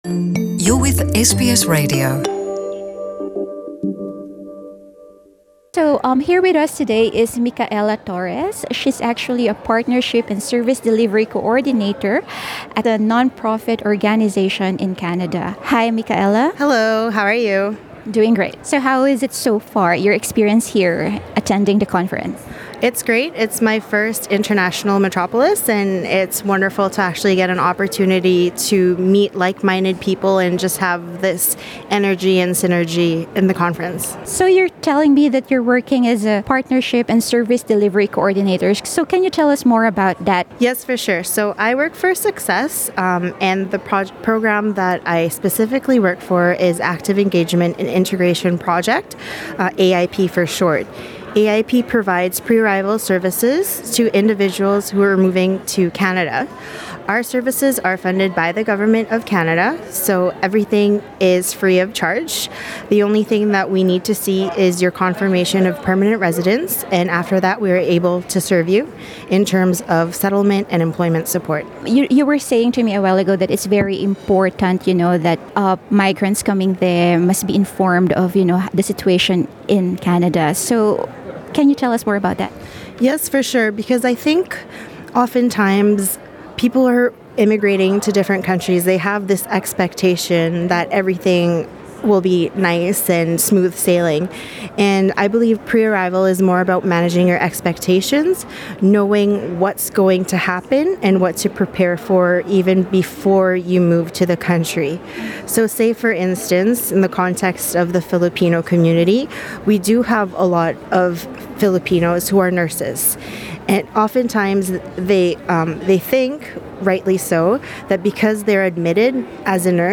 She shares in this interview the sense of happiness and achievement she feels serving the Filipino community.